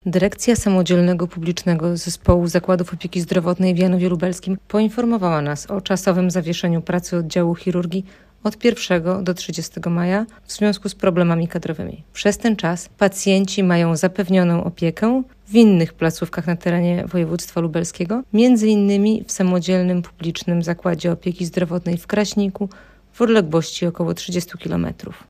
mówi w rozmowie z Radiem Lublin